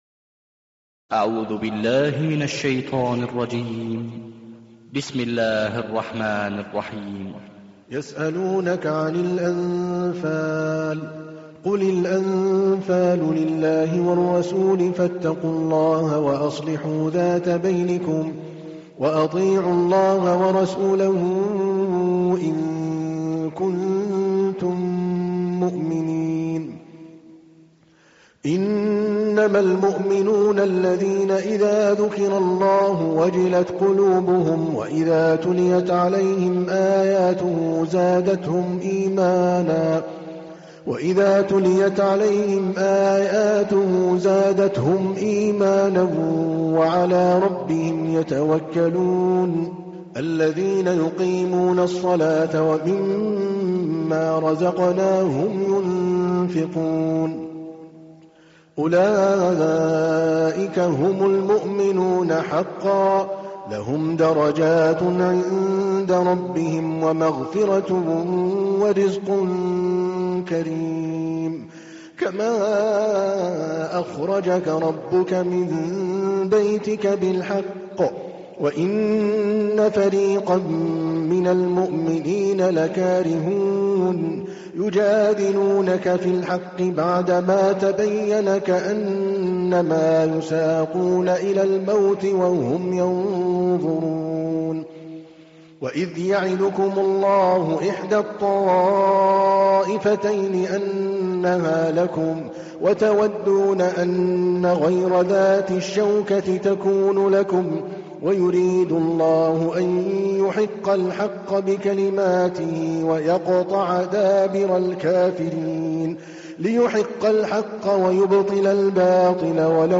تحميل : 8. سورة الأنفال / القارئ عادل الكلباني / القرآن الكريم / موقع يا حسين